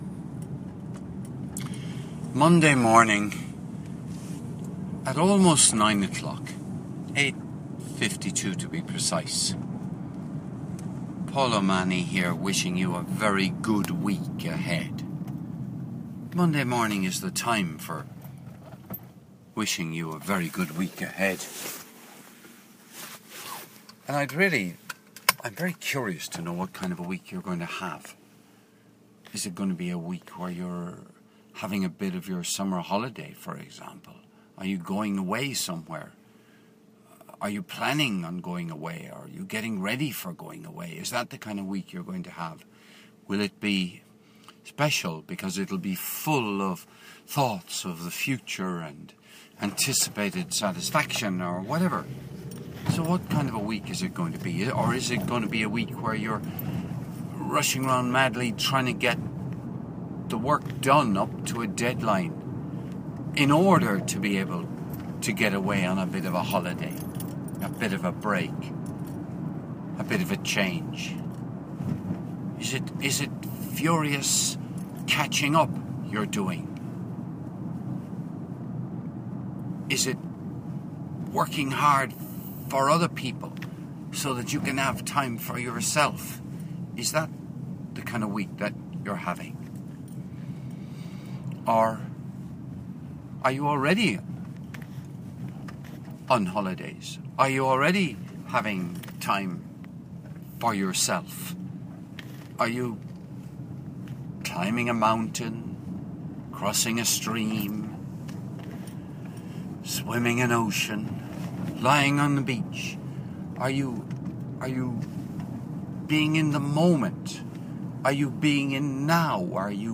Recorded on the morning of Monday 23 June 2014 - in my car after dropping my daughter to school in Cork Ireland.